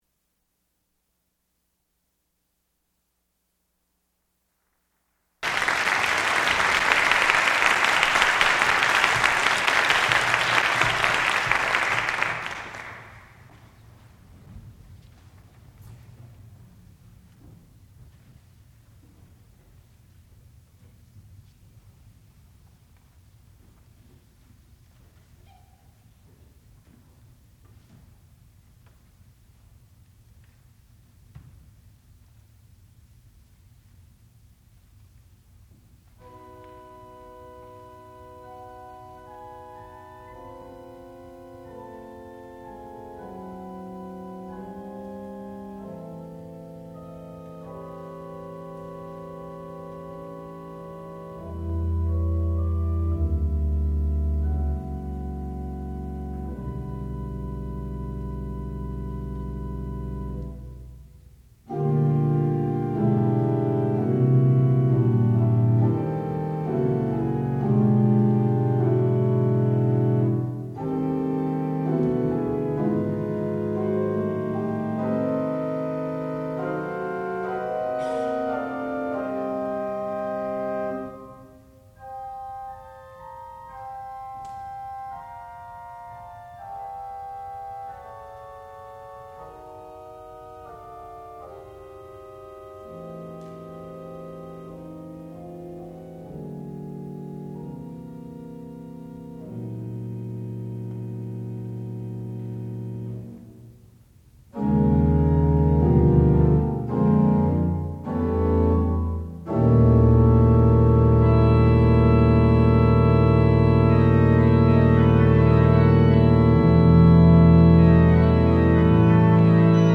sound recording-musical
classical music
organ
Master's Recital